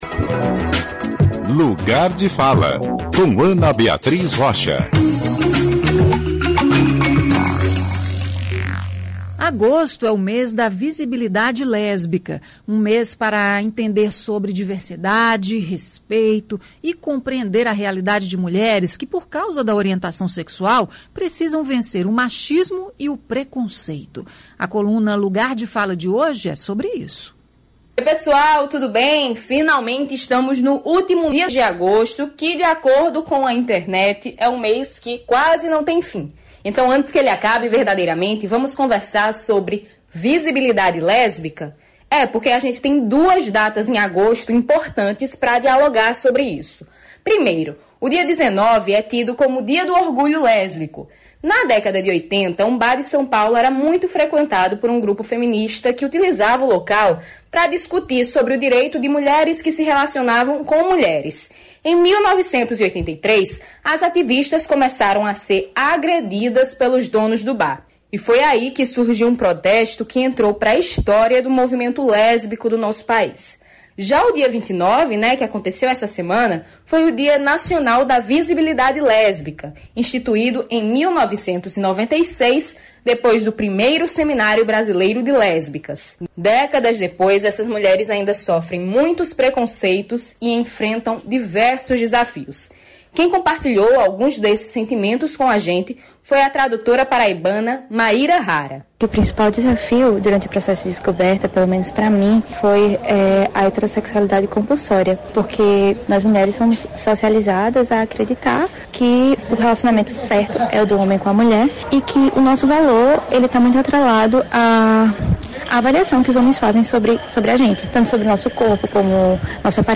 Clique no player e confira a coluna na íntegra: